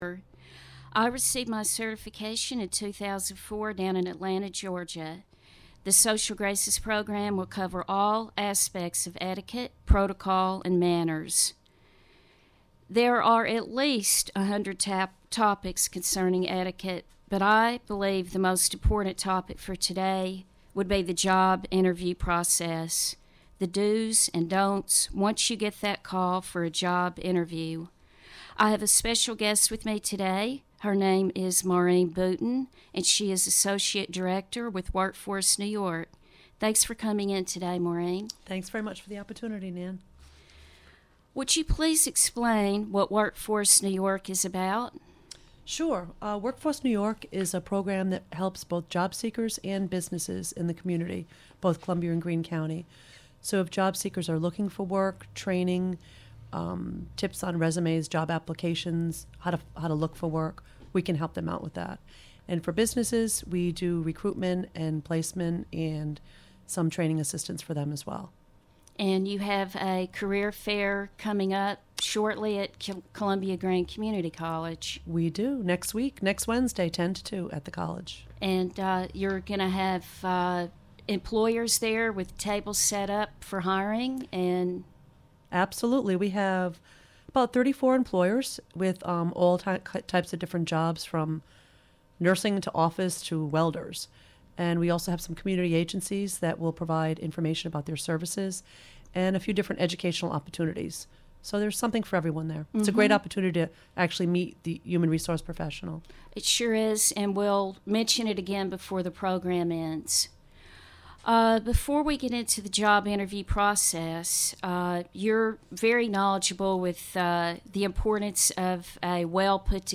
Monthly etiquette show.